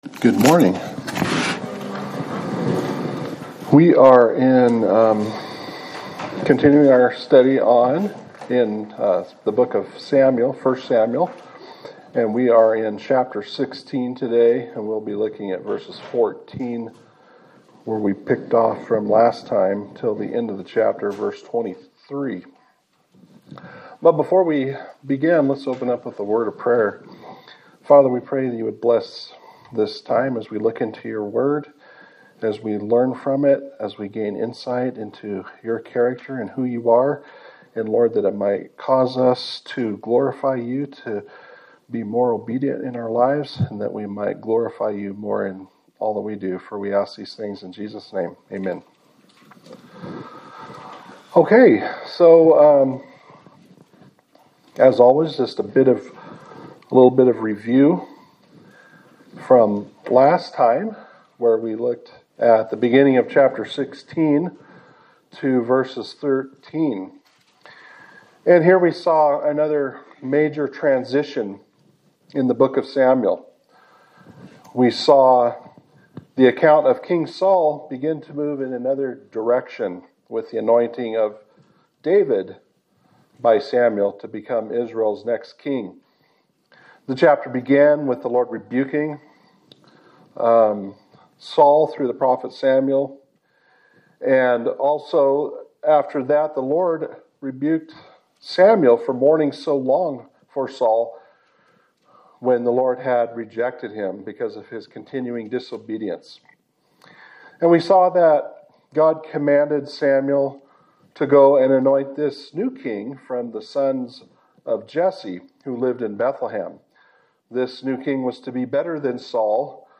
Sermon for March 15, 2026
Service Type: Sunday Service